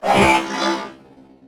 combat / enemy / droid / hurt3.ogg
hurt3.ogg